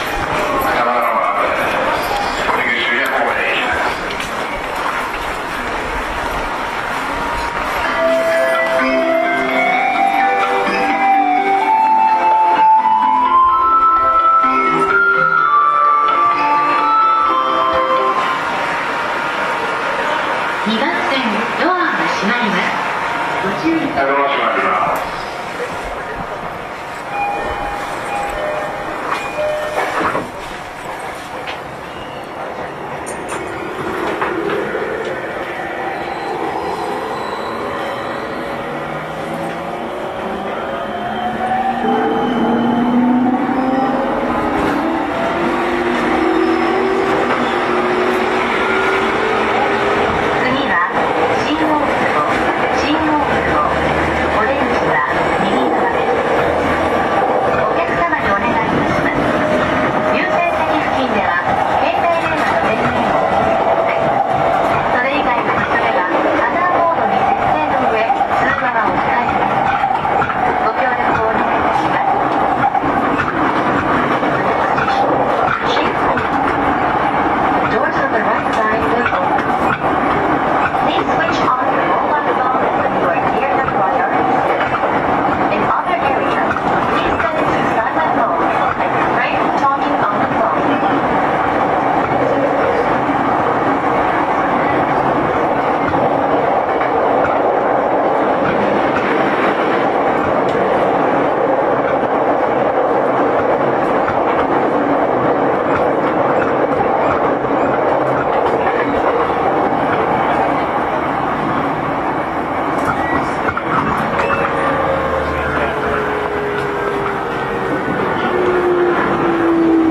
走行音
YM01 E231系 高田馬場-新大久保 2:21 8/10 --